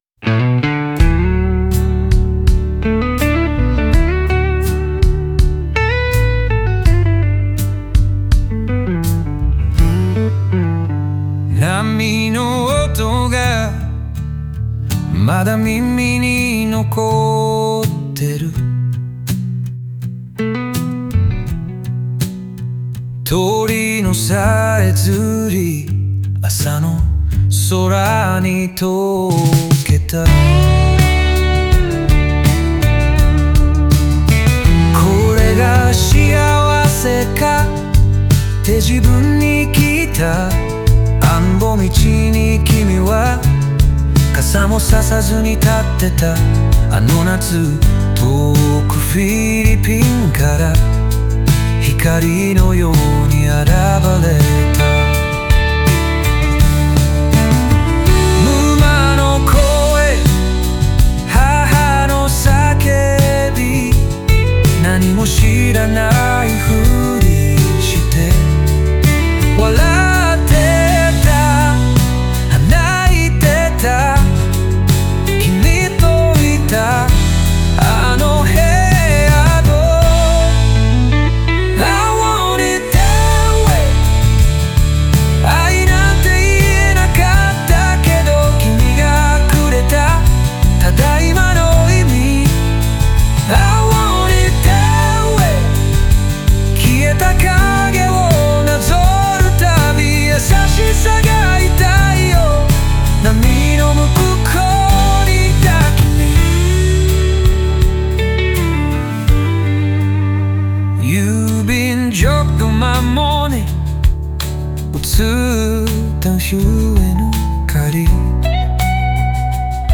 オリジナル曲♪
過去と現在が交差しながら、愛の不完全さと静かな祈りが響き合うバラードです。